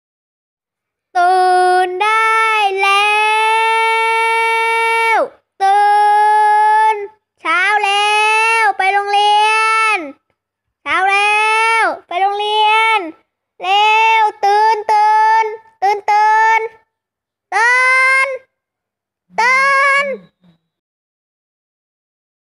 เสียงปลุกไปเรียนการันตีตื่น1000%
เสียงปลุก… กวนๆ เสียงปลุกกวนๆสารพัดคำด่า
หมวดหมู่: เสียงเรียกเข้า